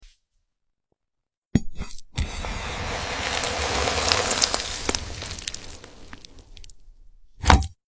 cutting glowing ball soothing sound effects free download
cutting glowing ball - soothing asmr